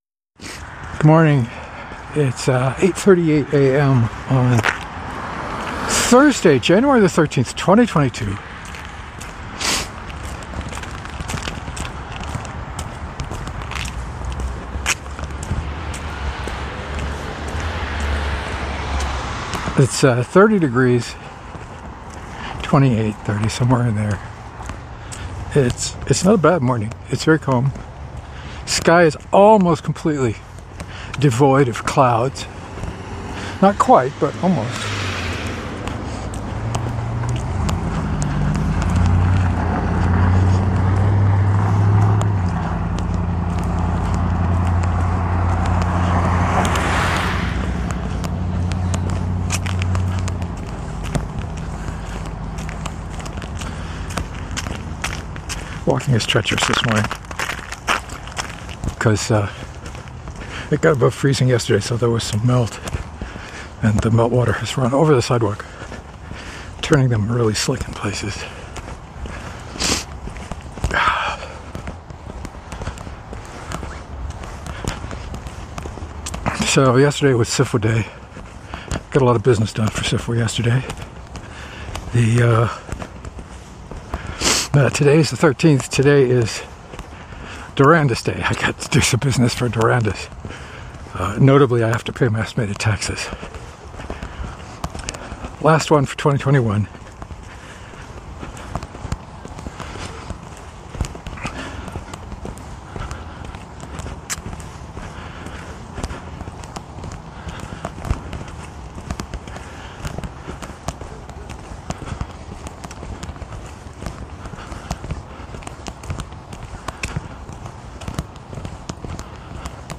Rambly talk today about how things are going here.